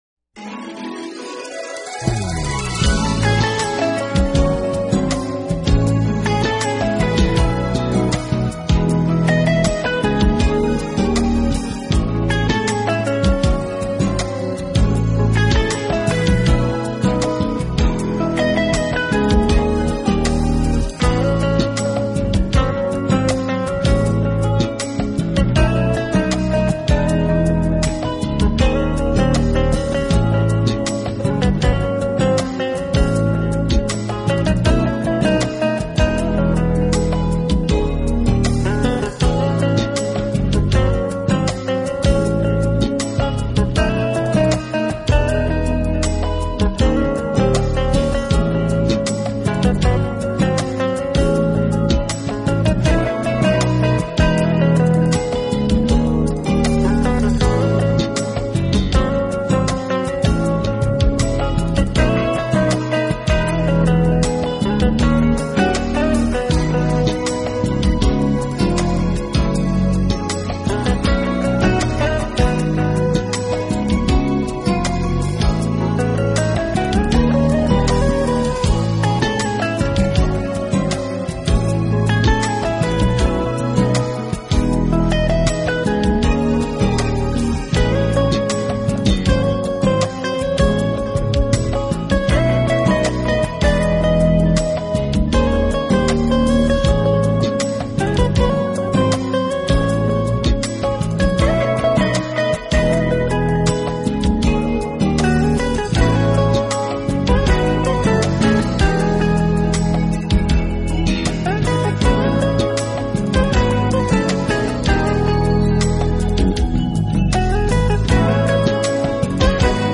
音乐类型：Jazz
简洁、利落，并配以少量的弦乐伴奏，在作品中时而会有著名歌手和乐手的加盟。